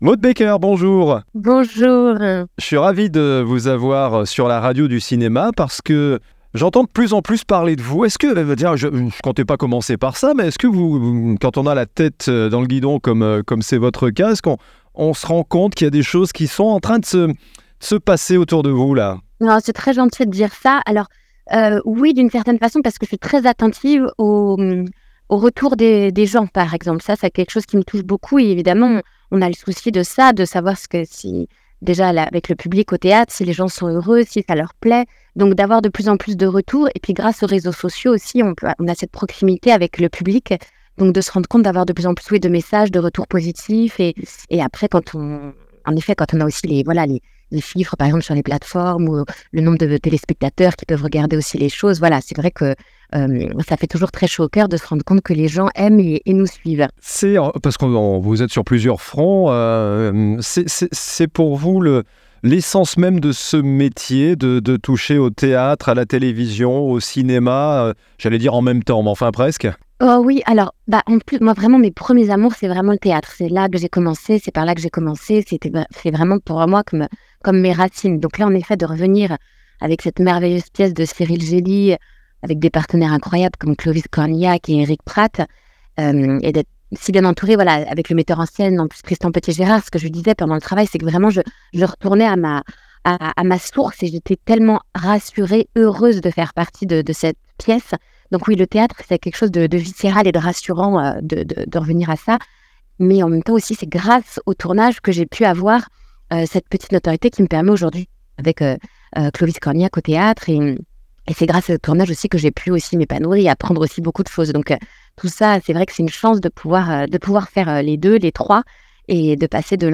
Lors de notre interview pour la radio du cinéma, Maud Baecker partage son attachement particulier au théâtre, qu’elle considère comme ses « premiers amours ». Elle évoque avec émotion son retour Dans les yeux de Monet, une pièce qui lui permet de renouer avec ses racines artistiques.